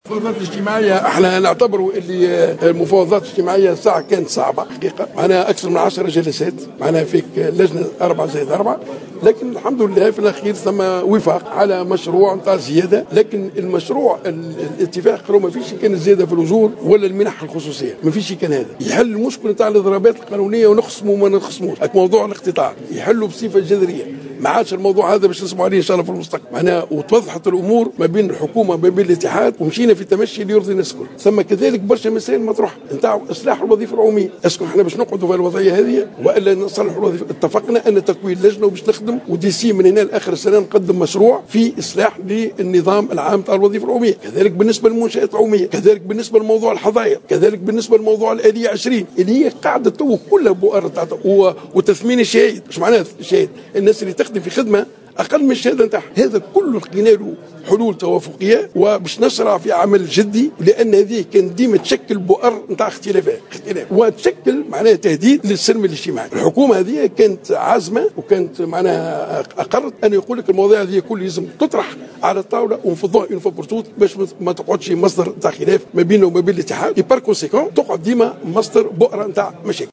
في تصريح